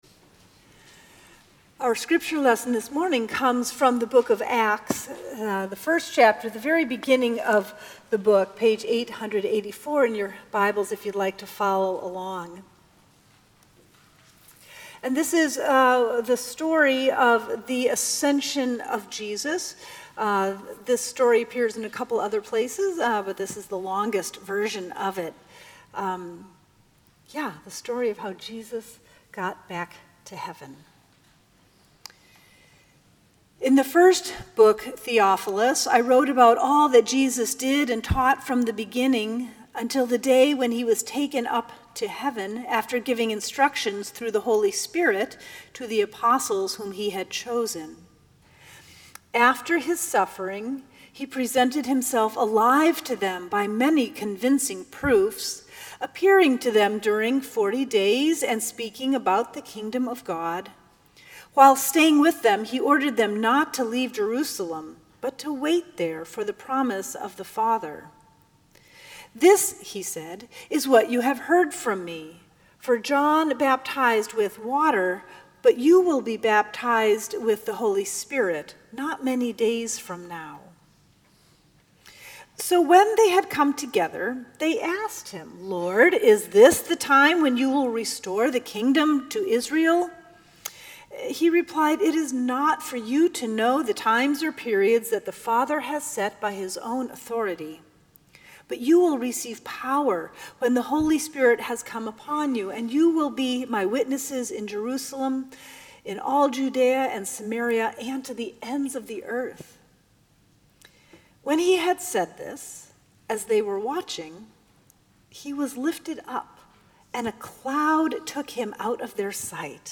Sermons at Union Congregational Church
May 13, 2018 Celebration of the Ascension